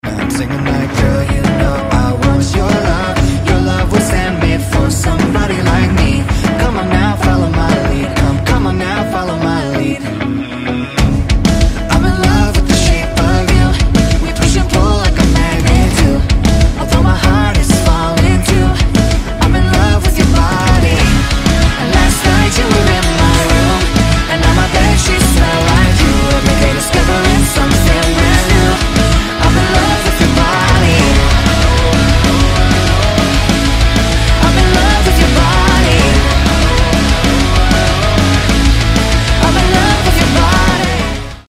Rock Cover